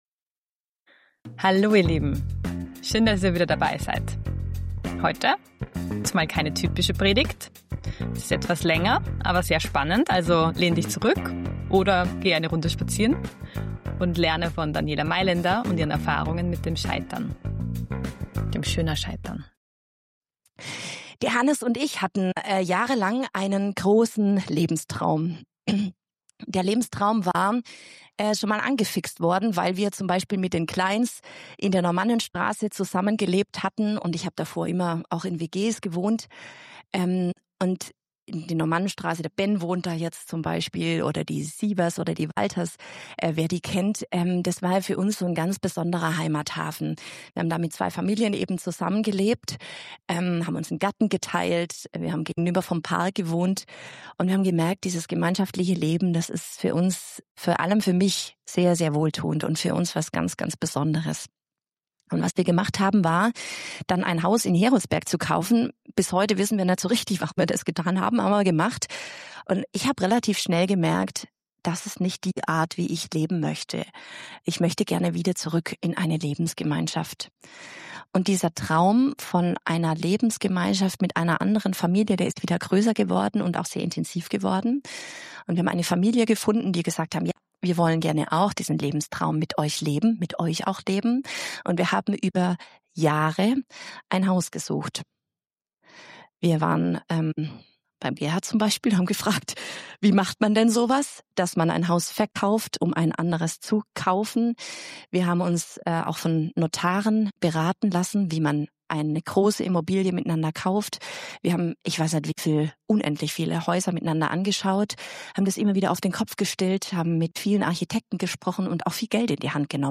Diese Predigt dreht sich um das Thema Scheitern – persönlich, strukturell und im Glauben. Sie zeigt auf, wie wir mit Niederlagen umgehen, daraus lernen und mutig weitergehen können, gestützt auf Gottes Gnade und Liebe.